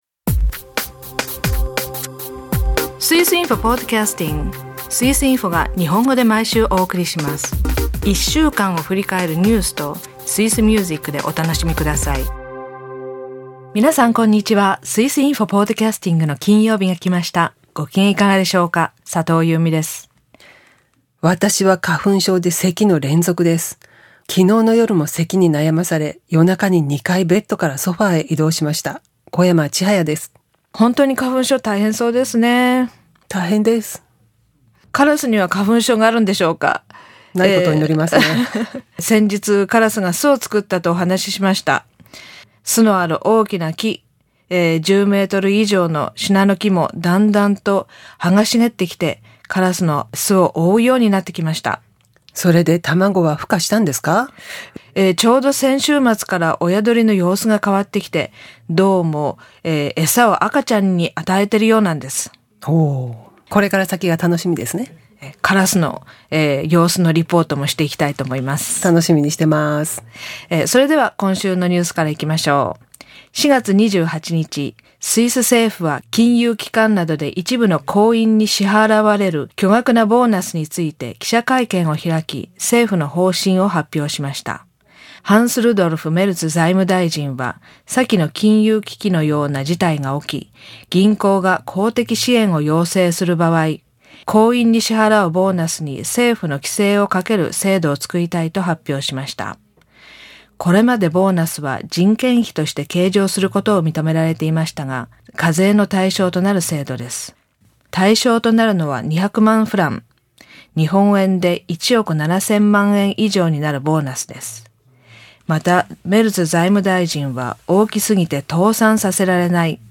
クリーンな大気で野菜が栄養不足。スイスミュージックは軽快なリズムの「ハワイ」。